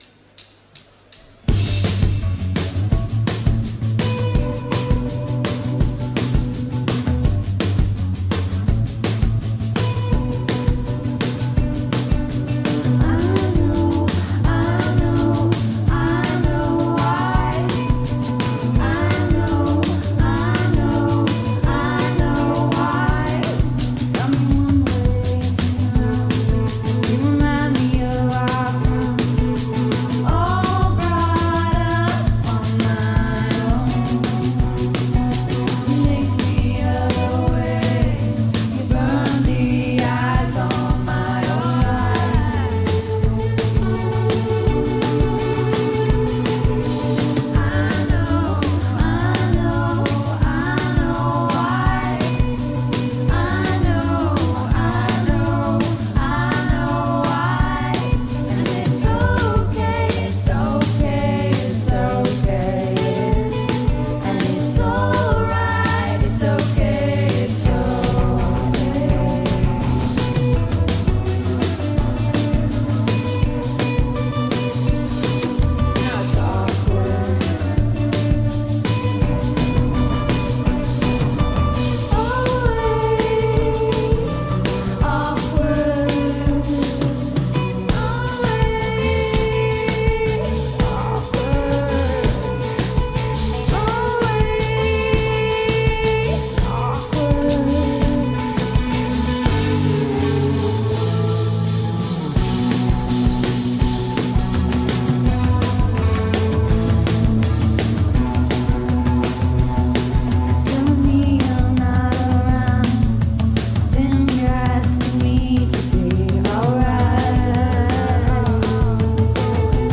recorded in concert